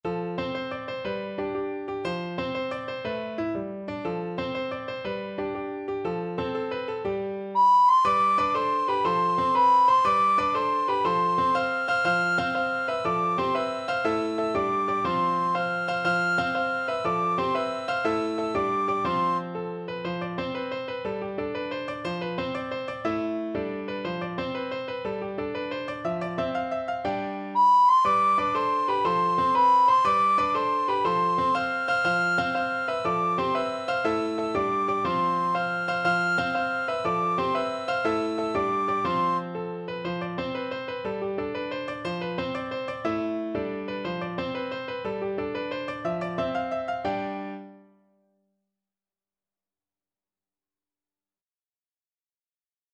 Free Sheet music for Soprano (Descant) Recorder
C major (Sounding Pitch) (View more C major Music for Recorder )
6/8 (View more 6/8 Music)
With energy .=c.120
Classical (View more Classical Recorder Music)